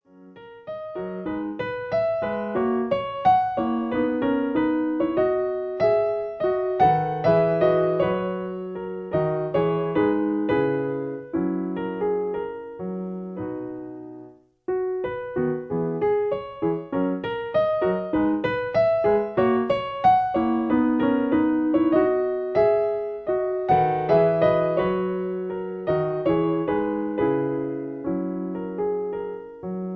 Piano accompaniment. In B